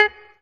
DreChron Guitar1.wav